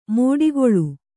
♪ mōḍigoḷu